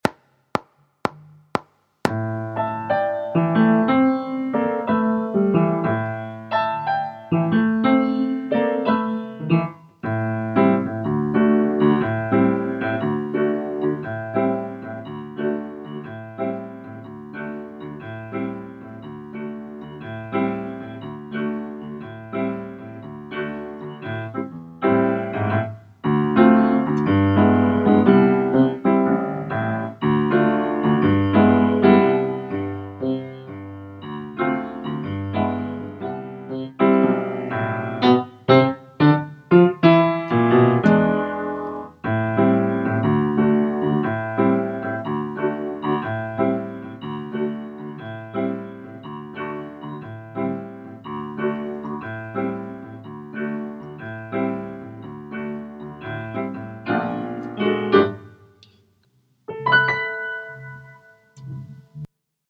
Chill Piano Backing